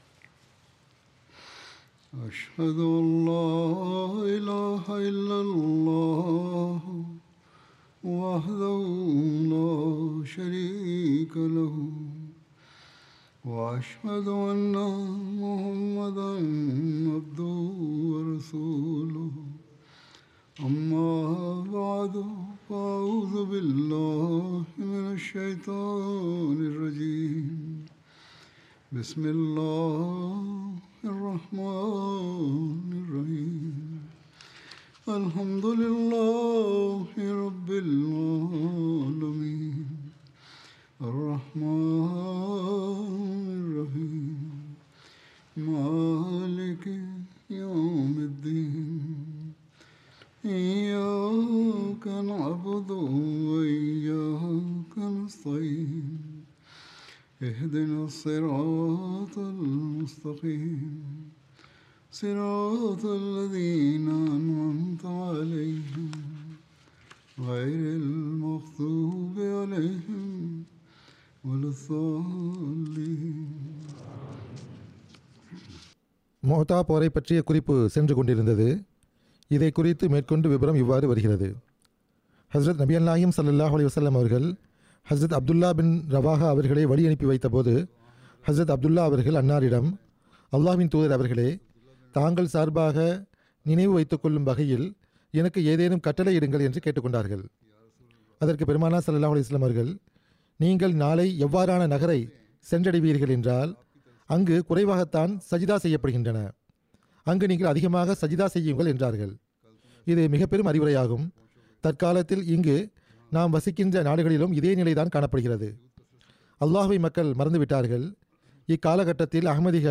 Tamil translation of Friday Sermon delivered by Khalifa-tul-Masih on February 21st, 2025 (audio)